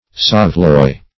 Saveloy \Sav"e*loy\, n.